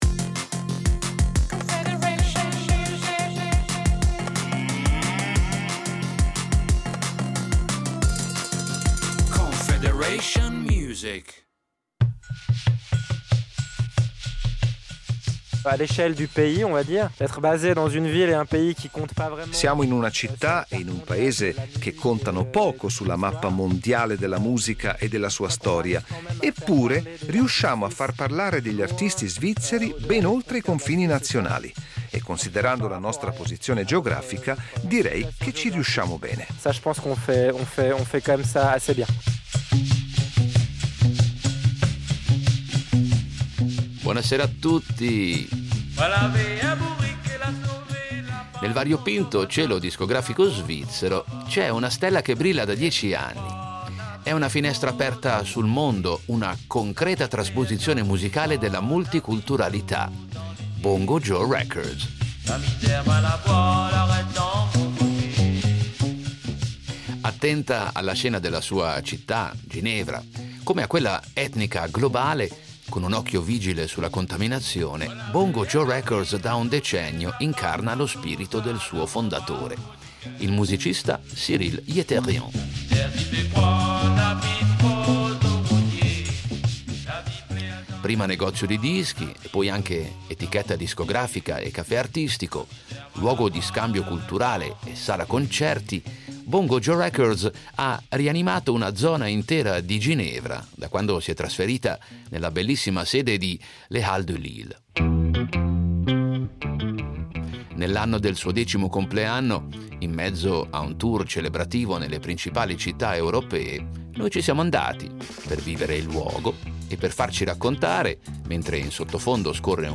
Musica etnica